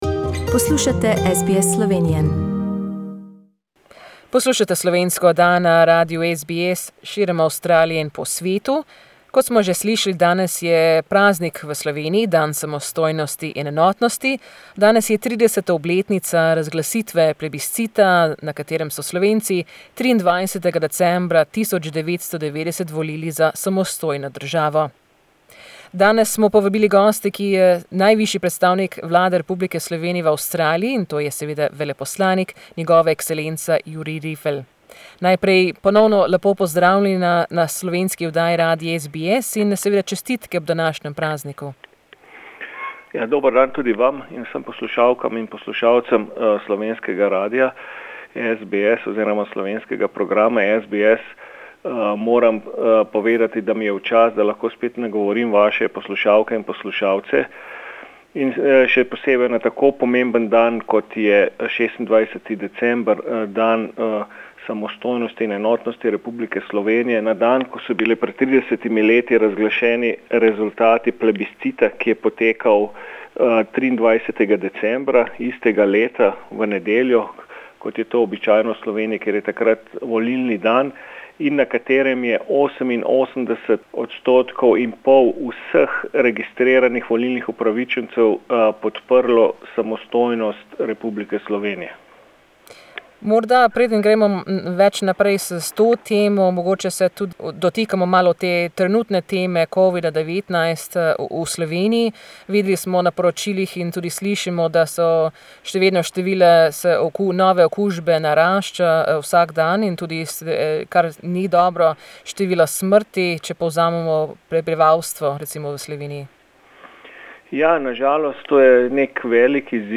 The Slovenian ambassador to Australia, his excellency Jurij Rifelj speaks to all Slovenians on the 30th anniversary of the announcement of plebiscite for Slovenian independence on 26th December, 1990.